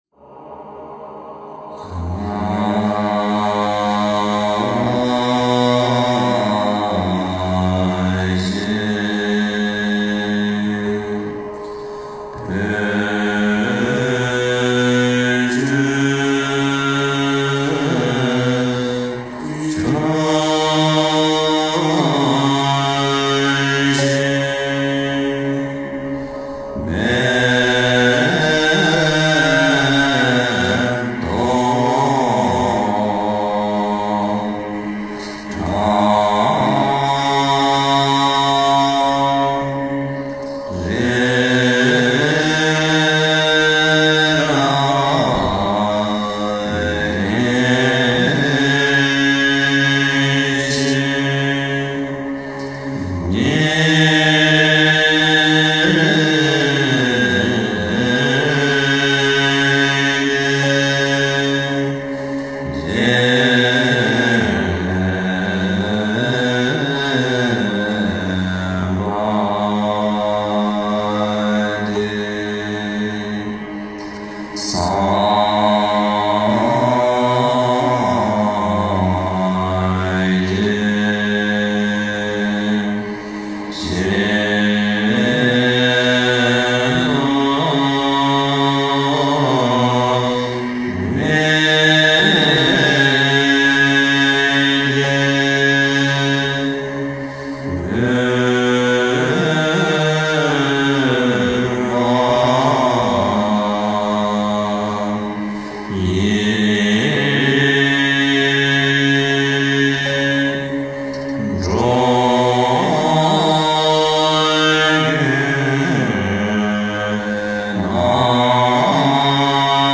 献曼达 诵经 献曼达--大宝法王 点我： 标签: 佛音 诵经 佛教音乐 返回列表 上一篇： 天地有灵 下一篇： 白月傳心 Transmitting the Mind under the Bright Moon 相关文章 佛说圣佛母般若波罗蜜多心经 佛说圣佛母般若波罗蜜多心经--王菲...